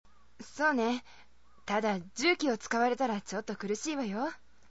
１８歳/女　一人称「私（わたし）」
ＳＡＭＰＬＥ　ＶＯＩＣＥ
ちょっぴりお姉さまの雰囲気があればいいなぁと思う。